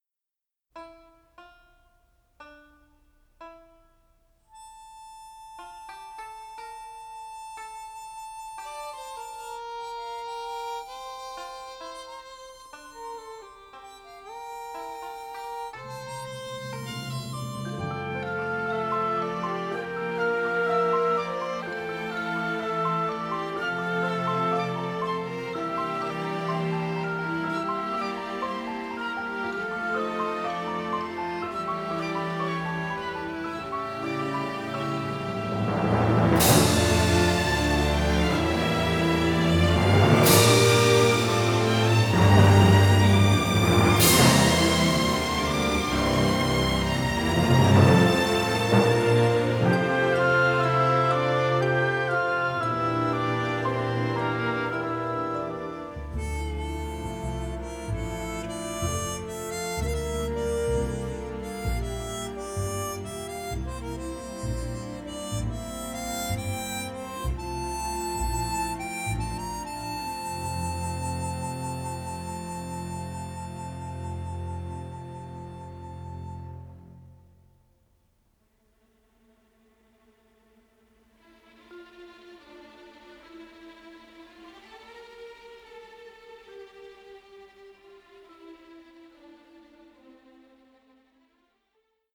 composed for full orchestra.